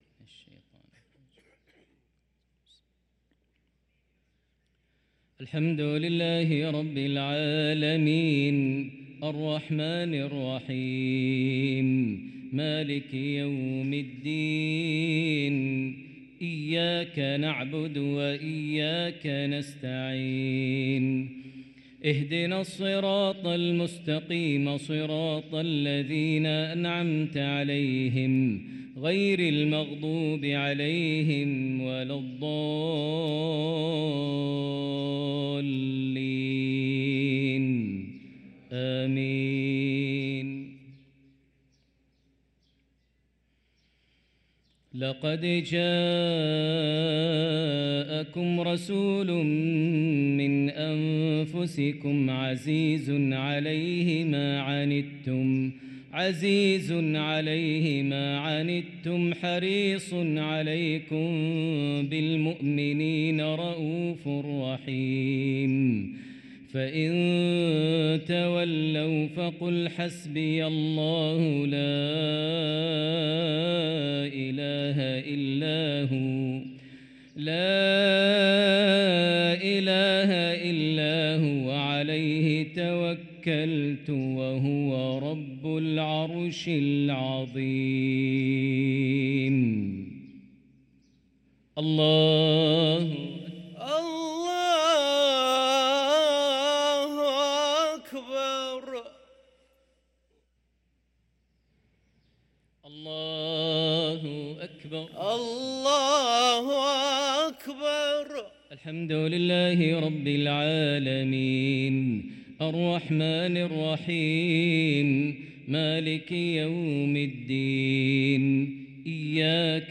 صلاة المغرب للقارئ ماهر المعيقلي 8 صفر 1445 هـ
تِلَاوَات الْحَرَمَيْن .